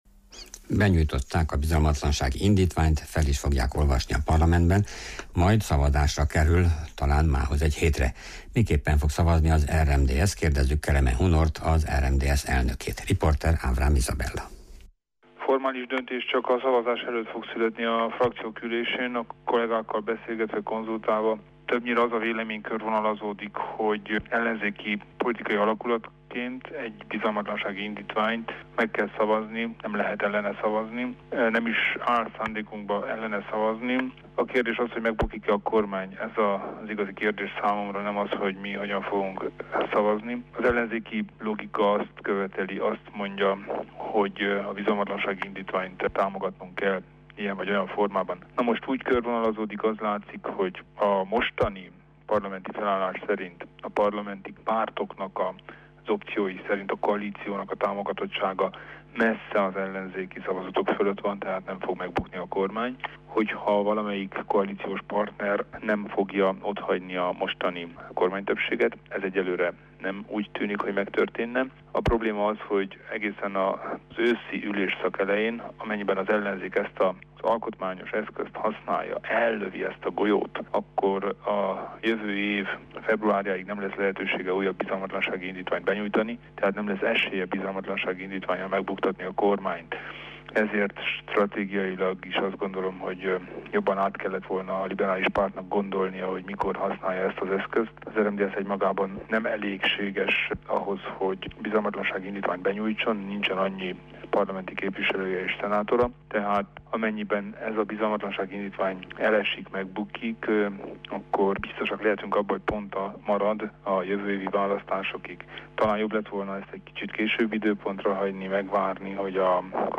Kelemen Hunor, az RMDSZ szövetségi elnöke nyilatkozott rádiónknak
Kelemen-Hunor-interju.mp3